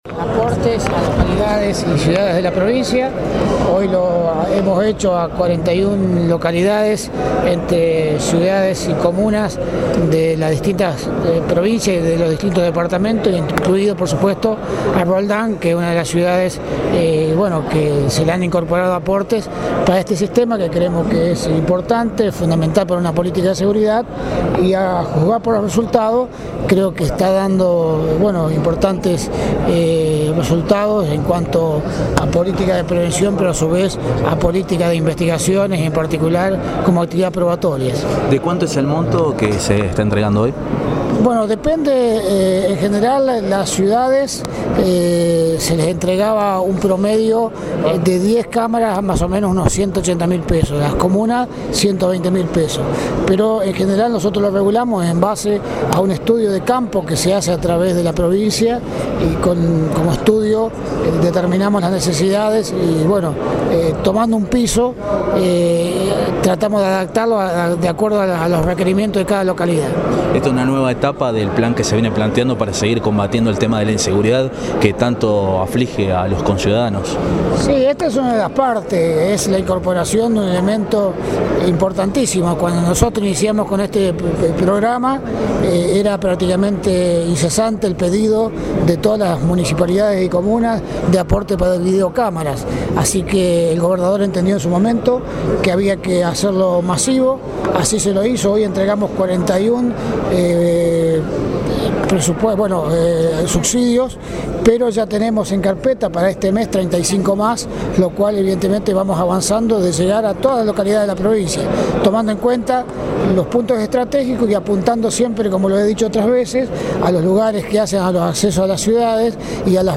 El acto se realizó en el Salón Blanco de Casa de Gobierno, con la presencia además del ministro de Seguridad, Raúl Lamberto; y del secretario de Estado de la Energía, Jorge Álvarez,